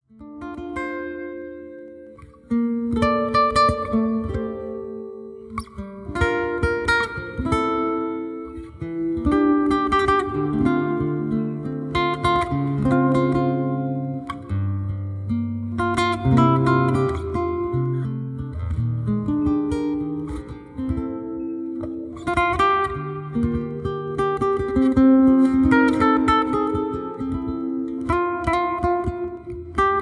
Solo Guitar Standards
Soothing and Relaxing Guitar Music